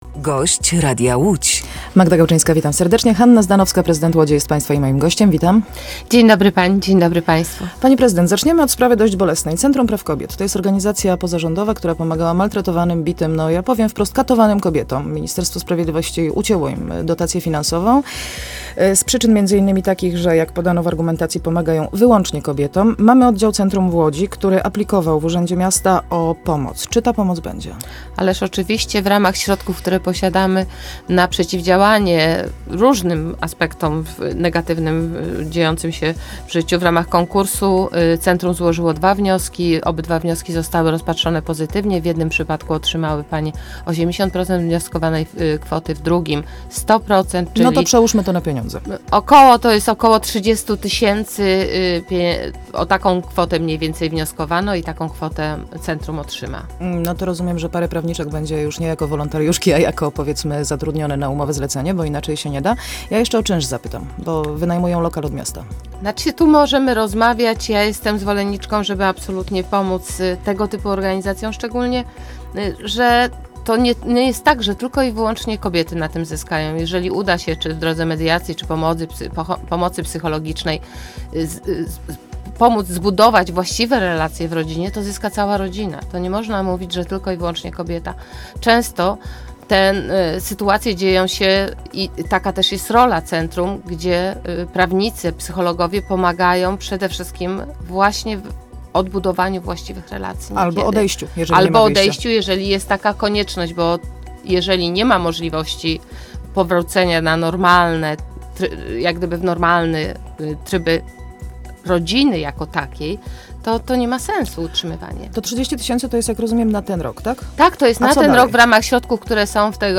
Posłuchaj rozmowy: Nazwa Plik Autor Hanna Zdanowska [Gość Po 8 w Radiu Łódź 20.05.2016] audio (m4a) audio (oga) Warto przeczytać Uszkodzone windy na trasie W-Z w Łodzi.